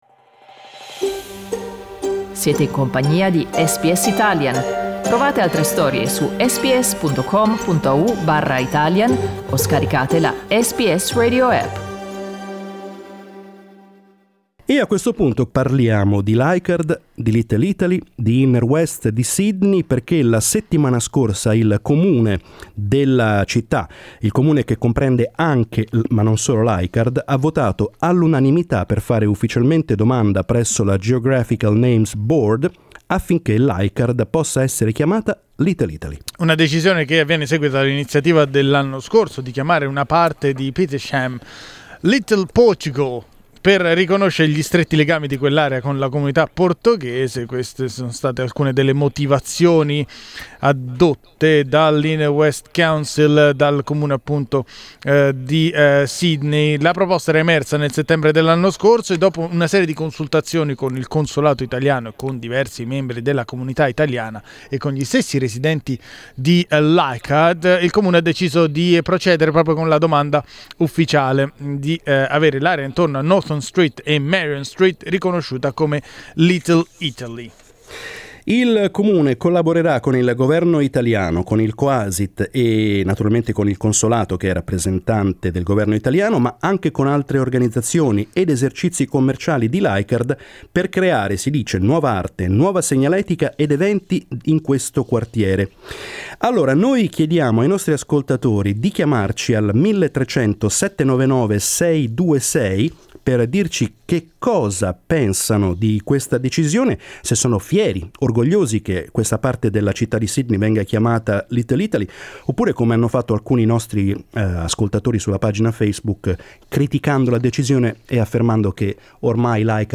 We discussed the topic with our listeners today: is an initiative that you agree with?